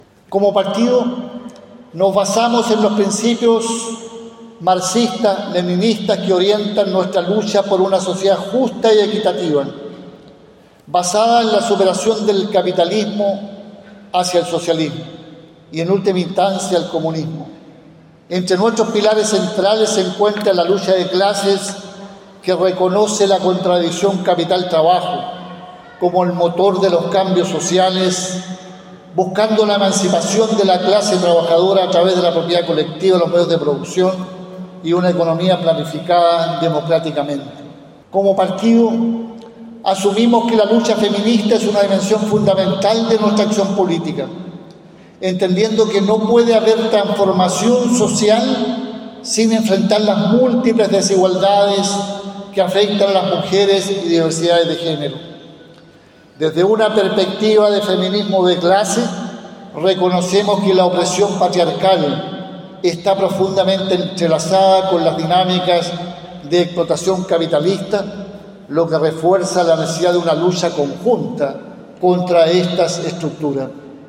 Durante la inauguración, el Presidente del Partido Comunista Lautaro Carmona, realizó un discurso en dónde abarcó de manera detallada los puntos más importantes de la política y el debate de los comunistas.